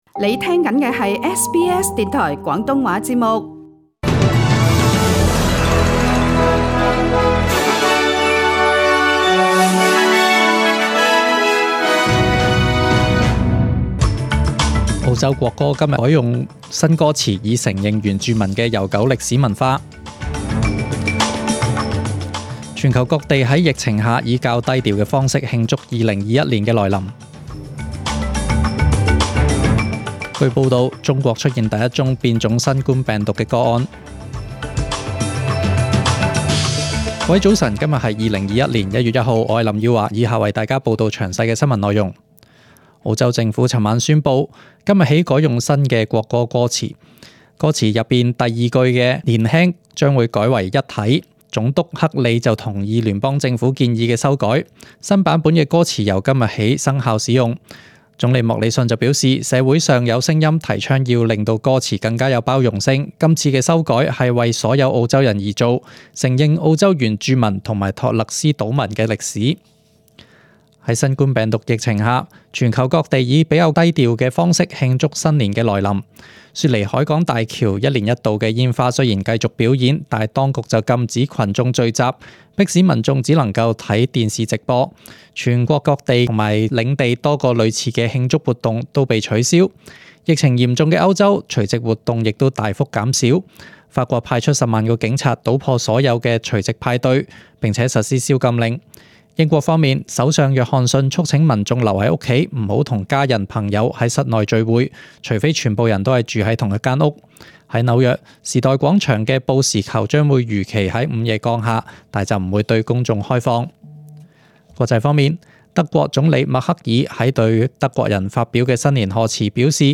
SBS 中文新闻 （一月一日）
请收听本台为大家准备的详尽早晨新闻。